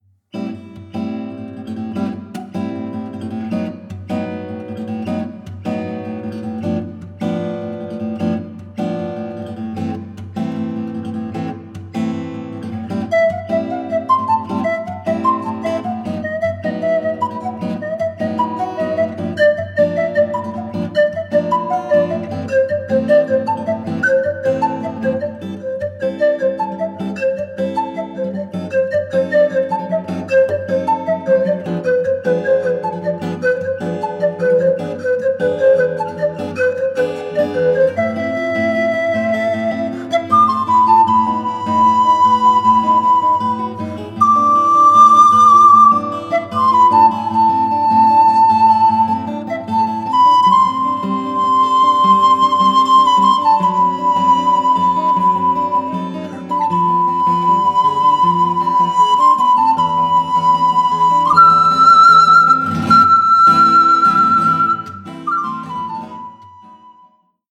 Panflöte
Gitarre